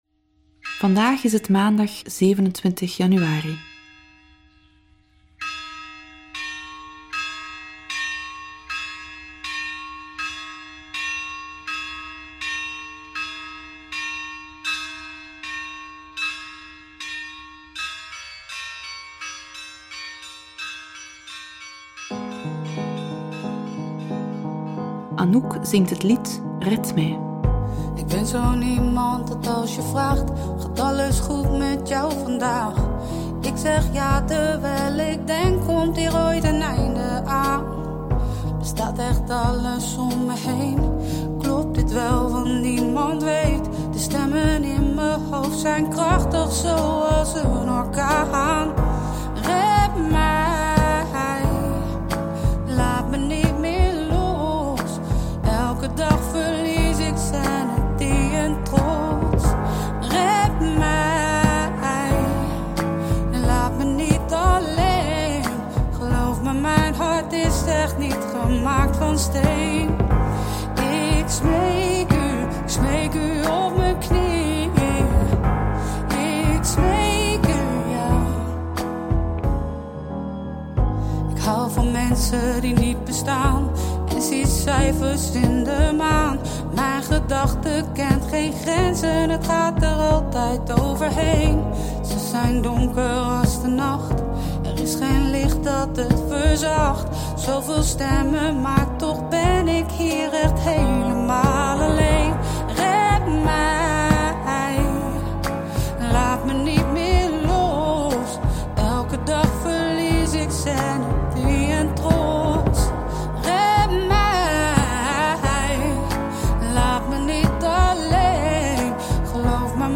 Bidden Onderweg brengt je dichter bij God, met elke dag een nieuwe gebedspodcast. In de meditaties van Bidden Onderweg staan Bijbelteksten central. De muzikale omlijsting, overwegingen y begeleidende vragen helpen je om tot gebed te komen.